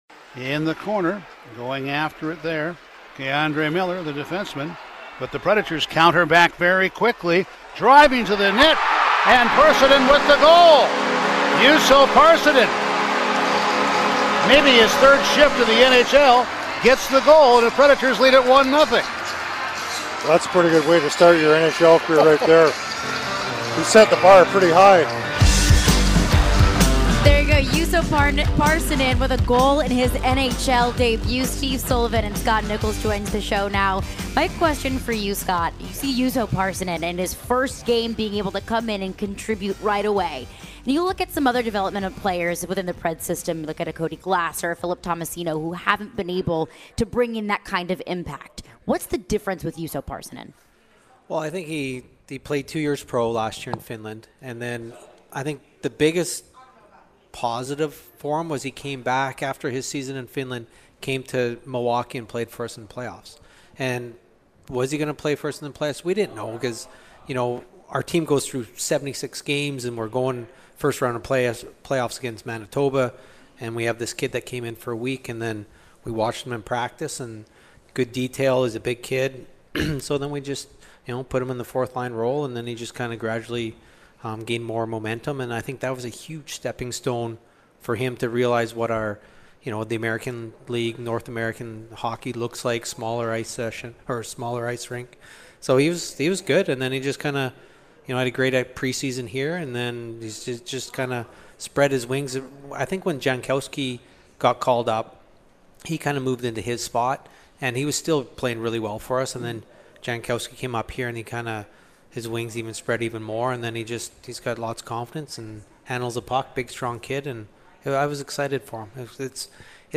Scott Nichol & Steve Sullivan interview (11-15-22)